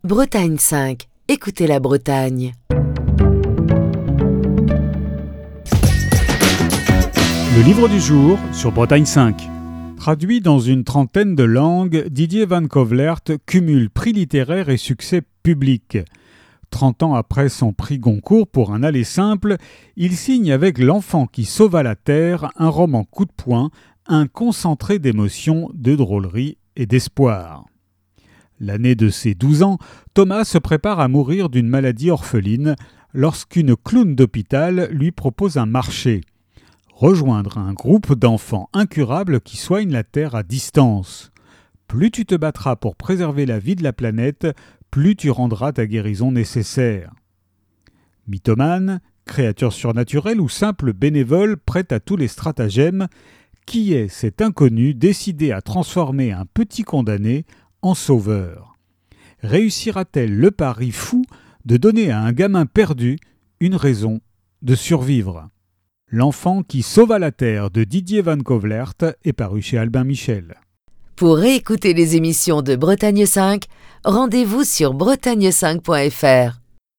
Chronique du 20 janvier 2025.